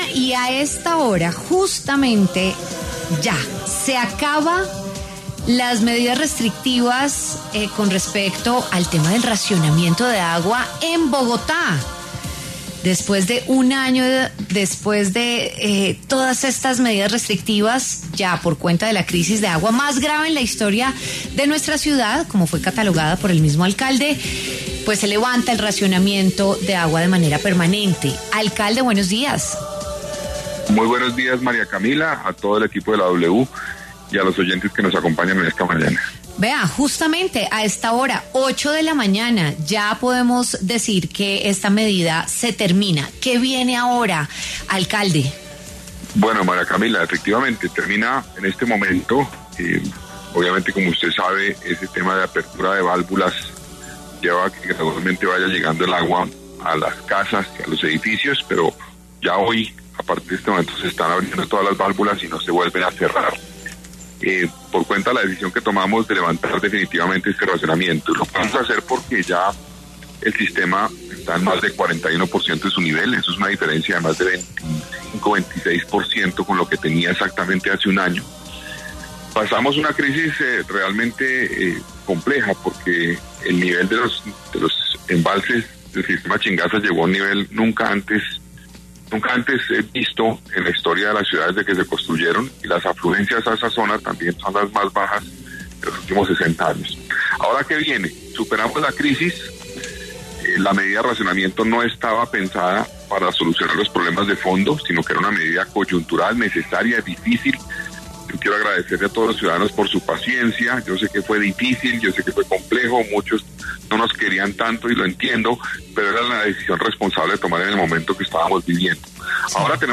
El alcalde de Bogotá, Carlos Fernando Galán, habló en W Fin de Semana sobre el fin del racionamiento de agua en la ciudad.